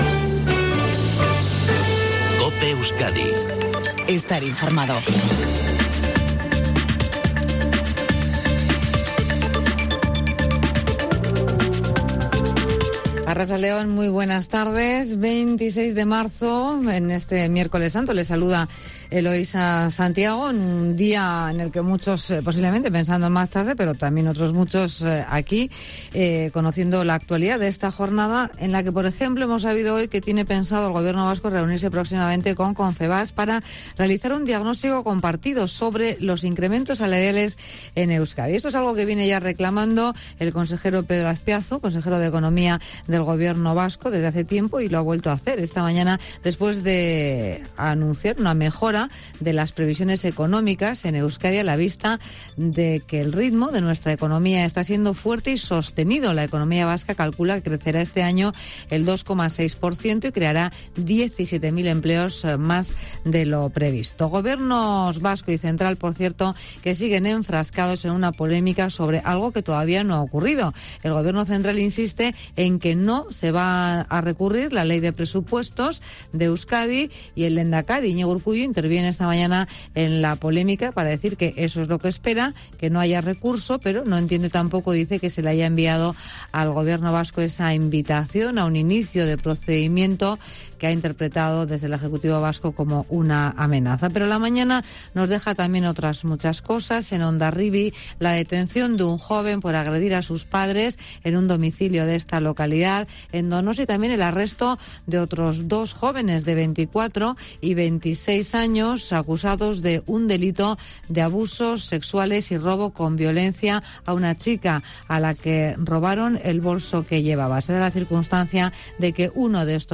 INFORMATIVO MEDIODÍA EUSKADI